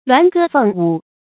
鸞歌鳳舞 注音： ㄌㄨㄢˊ ㄍㄜ ㄈㄥˋ ㄨˇ 讀音讀法： 意思解釋： 比喻美妙的歌舞。